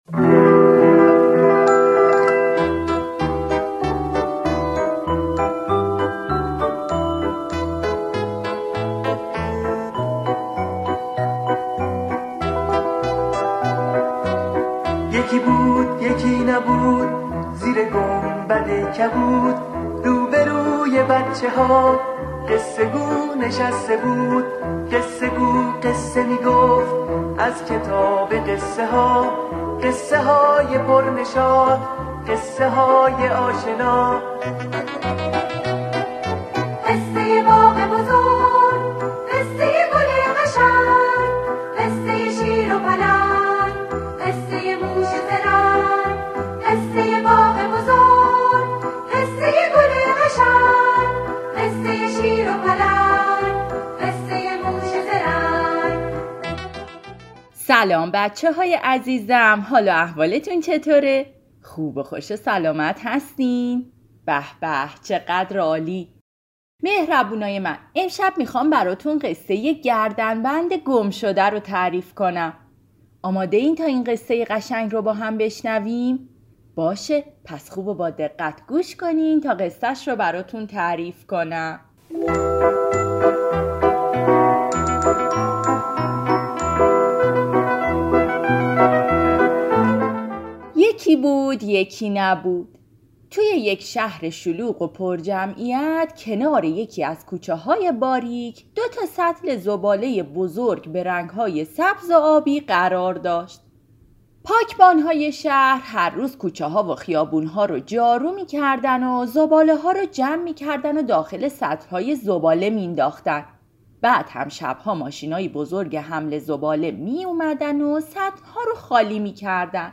کتاب صوتی گردنبند گمشده